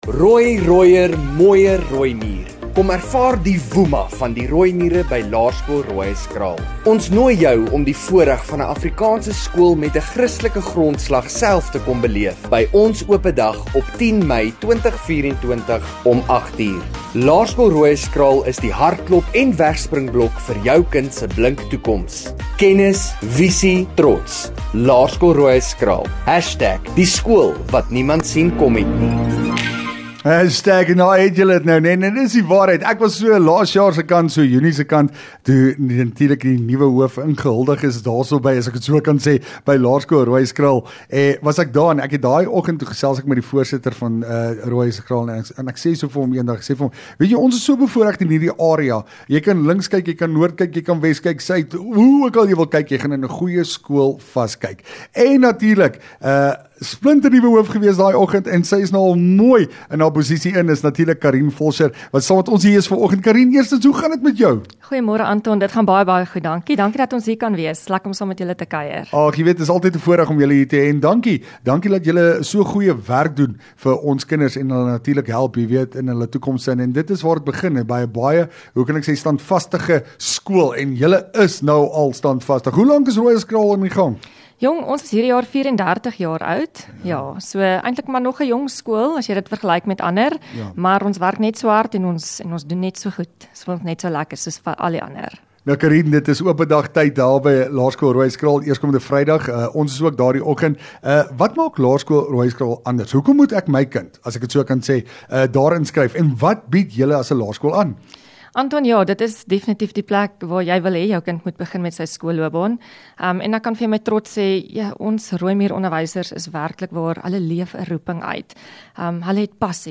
LEKKER FM | Onderhoude 8 May LS Roohuiskraal